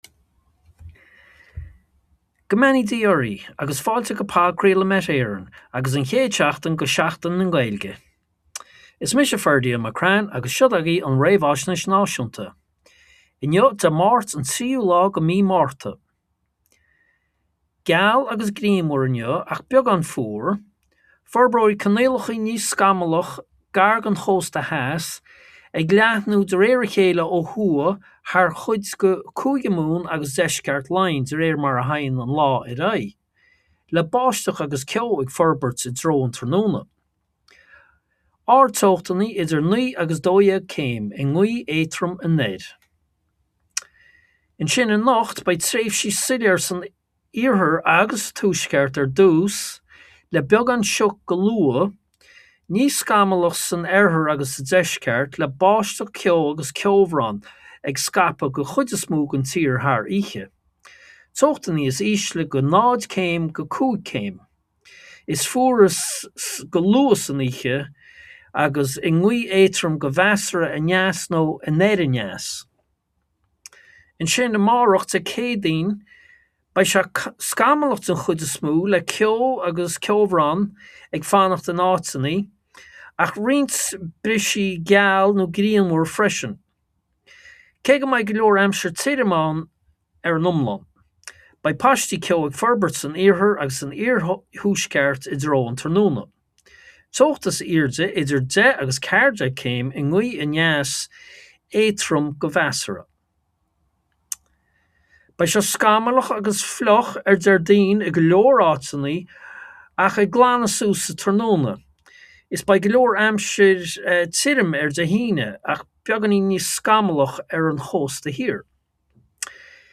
Weather Forecast from Met Éireann / Podchraoladh Met Éireann 3/3/26